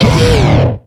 Cri de Hariyama dans Pokémon X et Y.